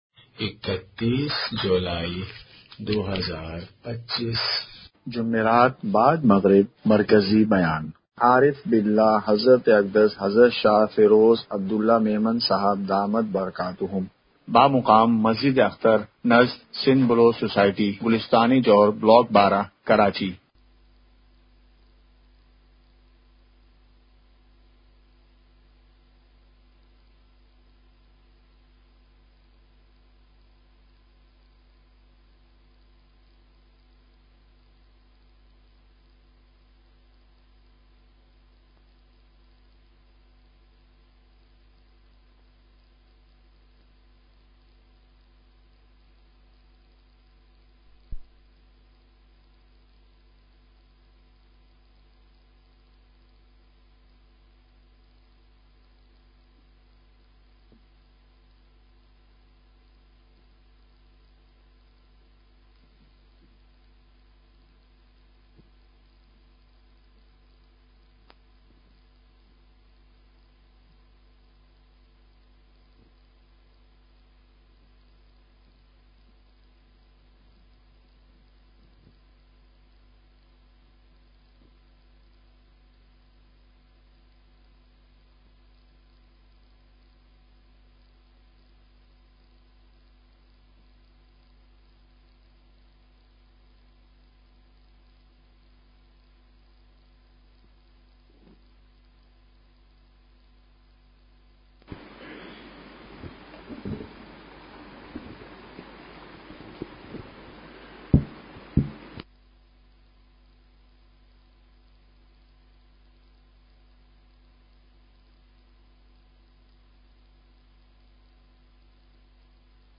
مقام:مسجد اختر نزد سندھ بلوچ سوسائٹی گلستانِ جوہر کراچی